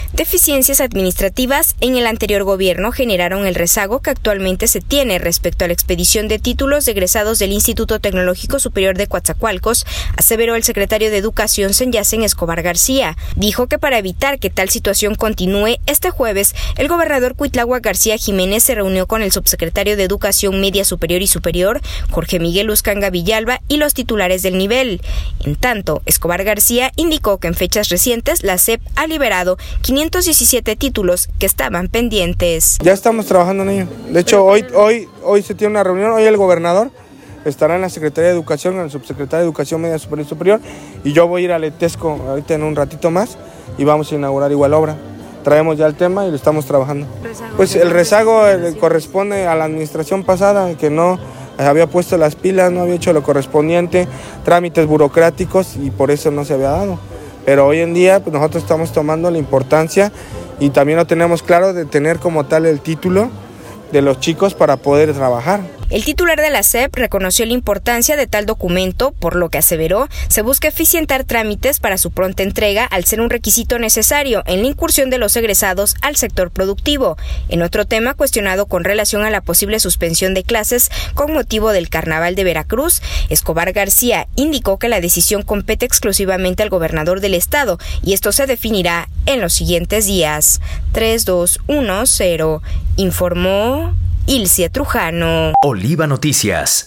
Entrevistado, reconoció la importancia de tal documento por lo que, dijo, se busca eficientar trámites para su pronta entrega, al ser un requisito necesario para la incursión de los egresados al sector productivo.